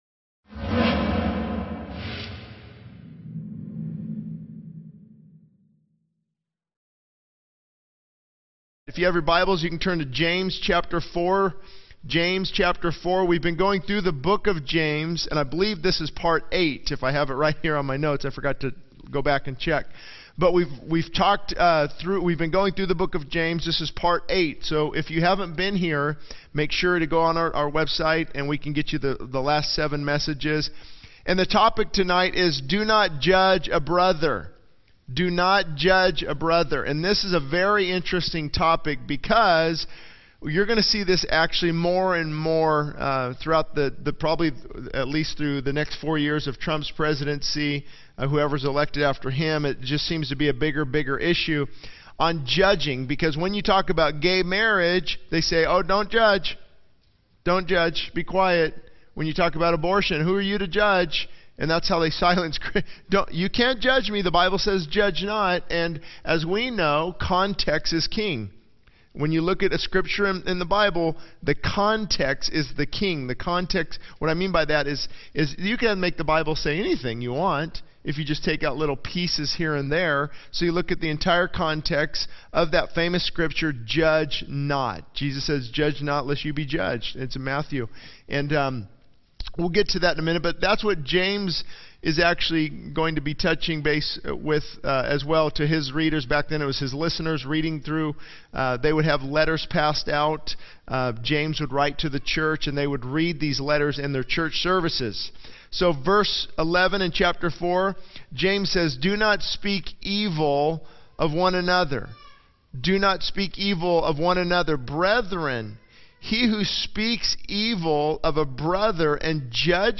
This sermon delves into the topic of not judging others, emphasizing the importance of understanding the context of biblical teachings on judgment. It highlights the need for self-examination before criticizing others and the significance of erring on the side of grace. The sermon also addresses the issue of gossip, the balance between truth and love, and the role of judgment within the church.